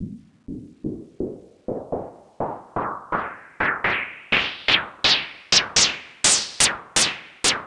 cch_fx_loop_climbing_125.wav